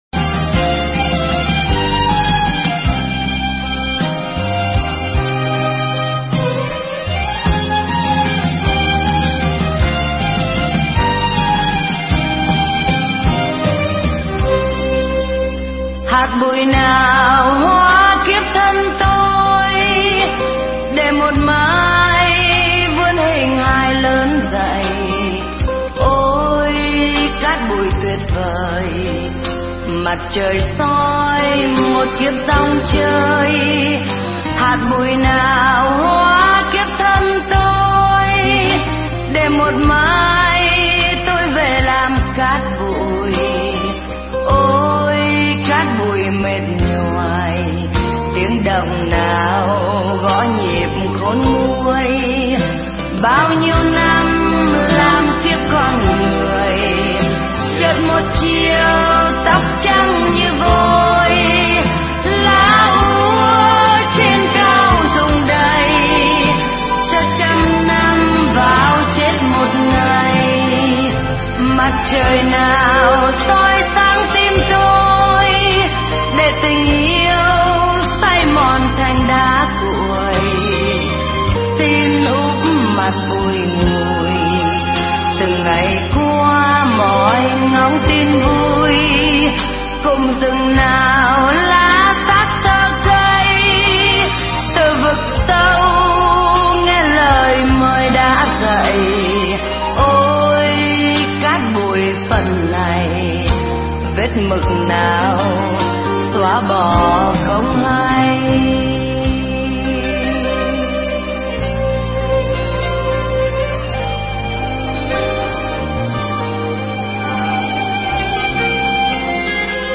Nghe Mp3 thuyết pháp Hạt Bụi Nào Hóa Kiếp Thân Tôi